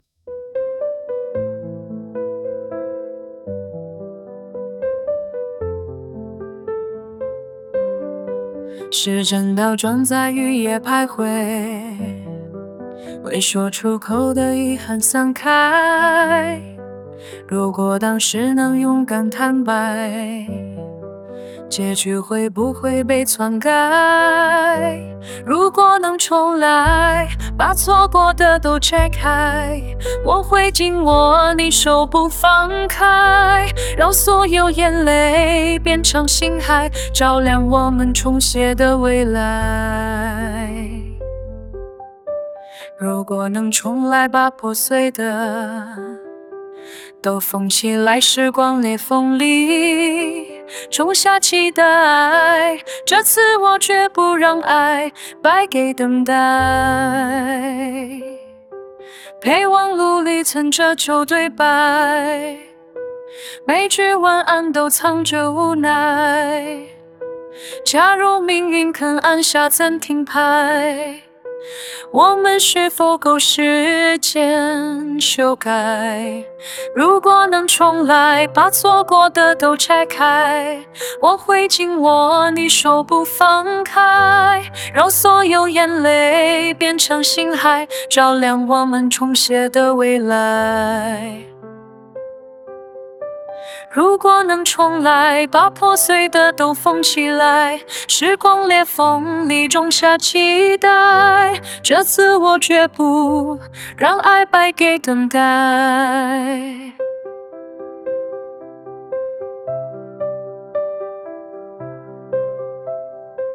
MusicFayIn is an advanced AI-powered music generation system that creates complete musical compositions from lyrics and style parameters.